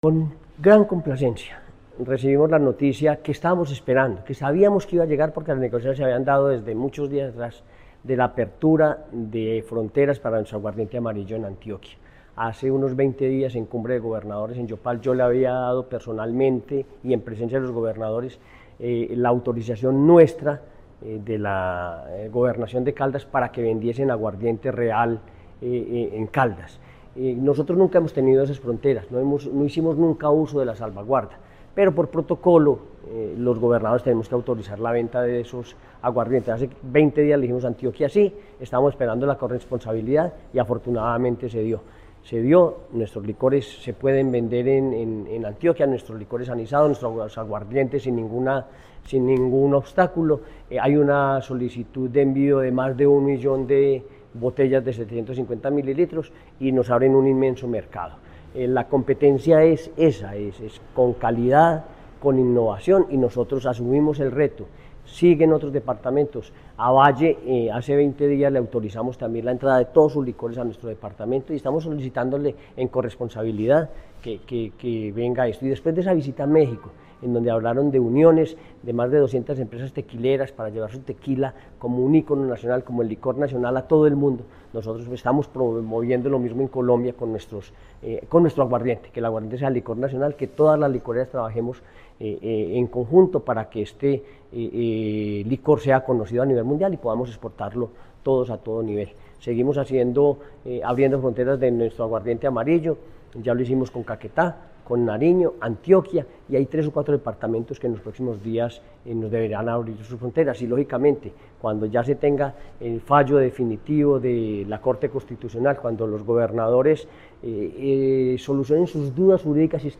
Henry Gutiérrez Ángel, gobernador de Caldas (Comercialización del Aguardiente Amarillo en Antioquia)